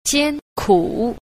10. 艱苦 – jiānkǔ – gian khổ